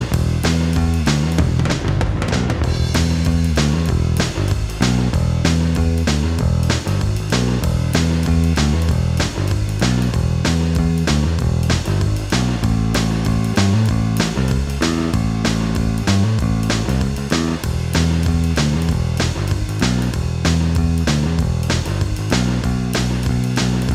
Minus Guitars Rock 3:57 Buy £1.50